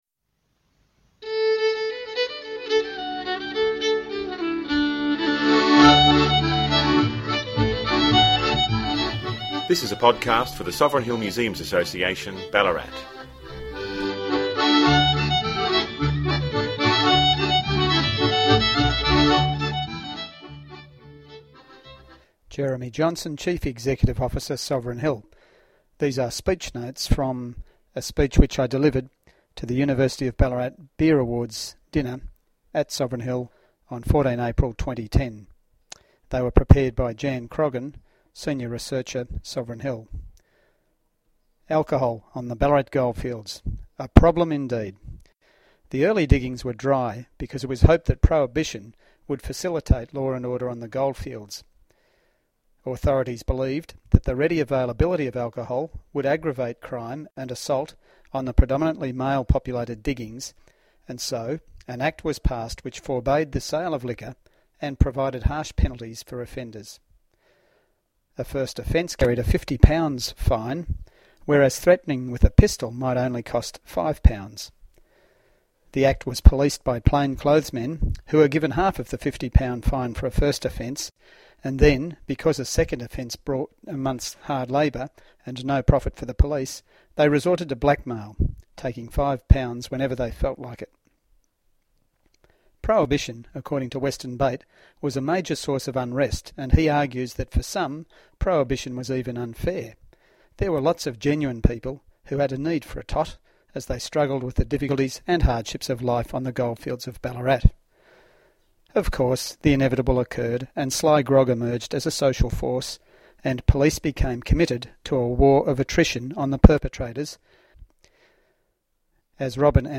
The speech was recorded and an audio file is available at the end of this blog.